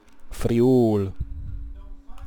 Friuli (Italian: [friˈuːli]; Friulian: Friûl [fɾiˈuːl]